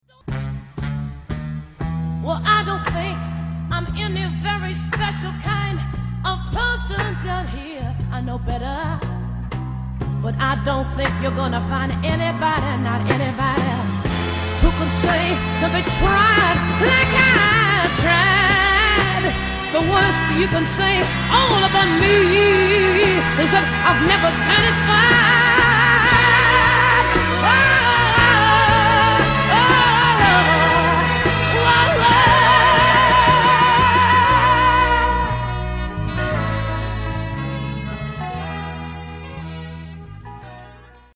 particolarmente intensa (la voce ragazzi, la voce!)